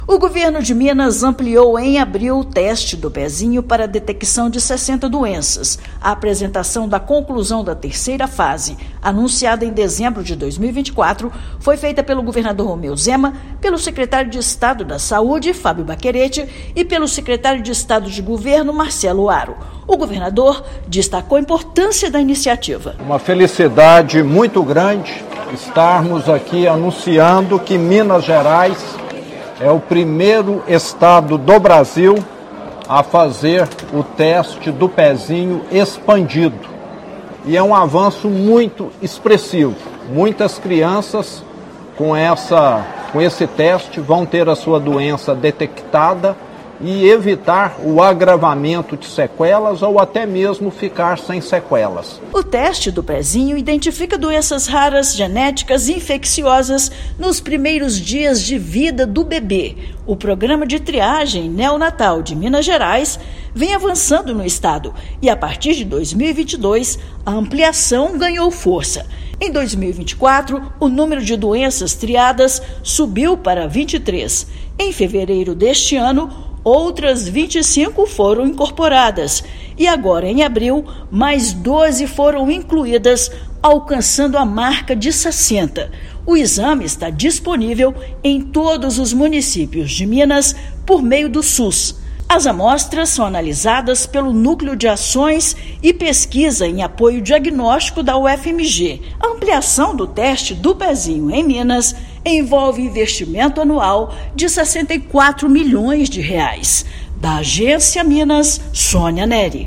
Governo do Estado alcança marca de 60 doenças triadas, garantindo diagnóstico precoce, tratamento especializado e mais qualidade de vida para os bebês mineiros. Ouça matéria de rádio.